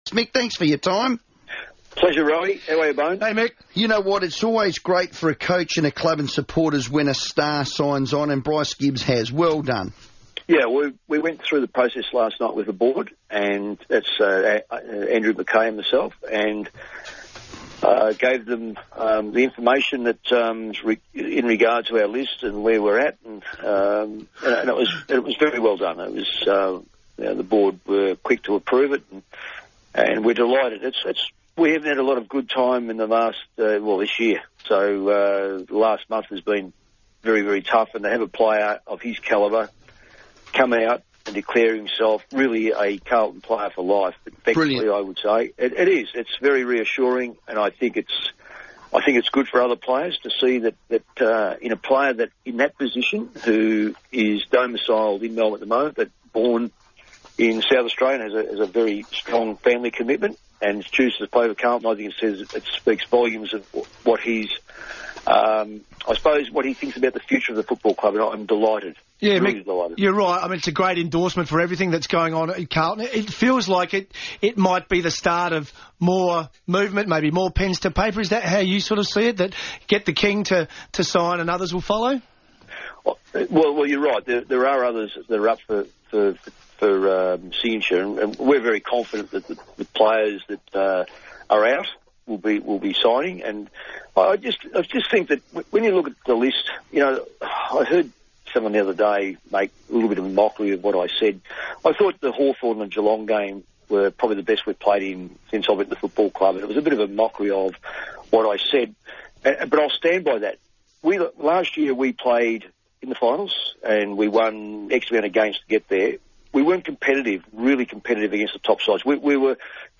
Senior Coach Mick Malthouse spoke to Adelaide's FIVEaa on Tuesday, June 24.